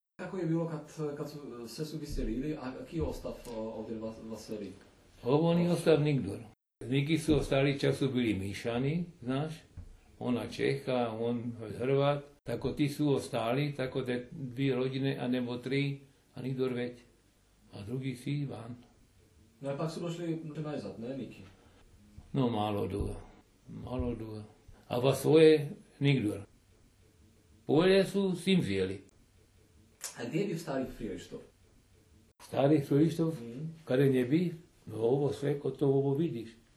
Moravska 2 – Govor
Moravski Hrvati, Mährischen Kroaten, Mähren, Tschechien
„možda Frielistof/Jevišovka“, Moravska 2008.